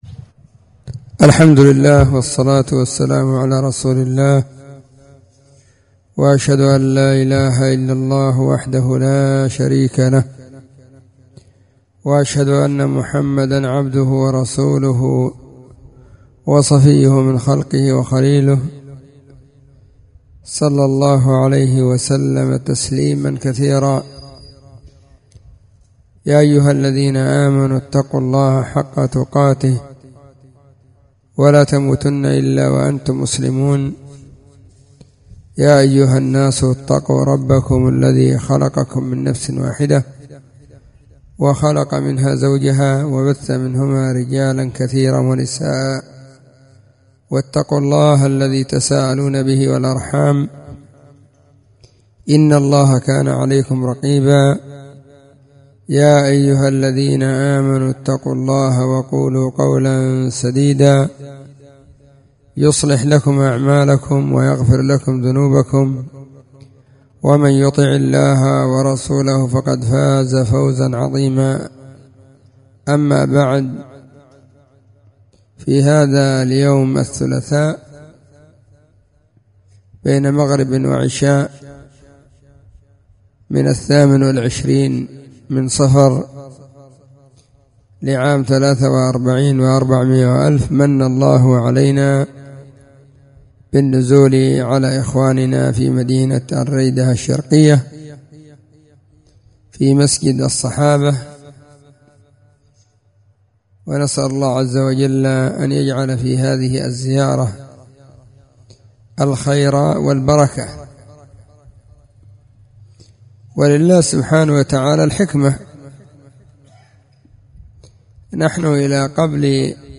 محاضرة, في مسجد الصحابة ,بمدينة الريدة الشرفية, 28 صفر 1443
📢 مسجد الصحابة – بالغيضة – المهرة، اليمن حرسها •اللّـہ.